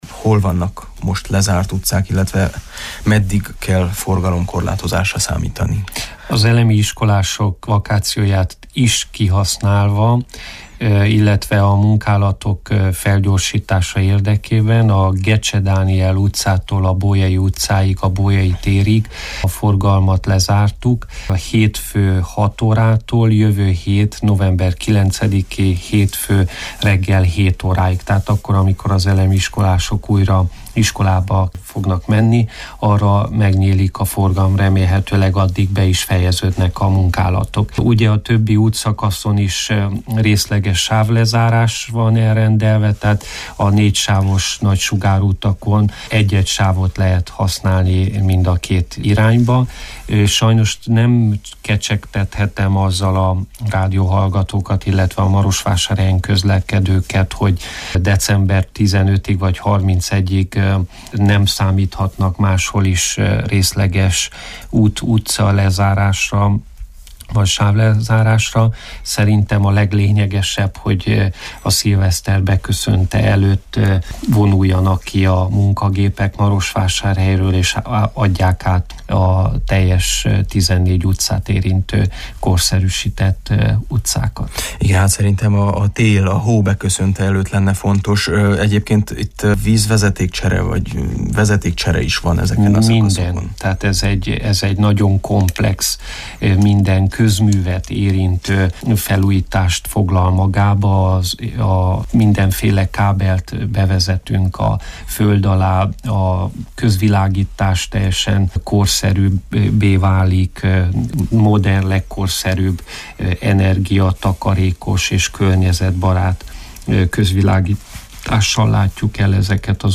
A kérdésre Peti András polgármester-helyettes válaszoé. A mai Miben segíthetünk című beszélgetőműsorunkból idézünk.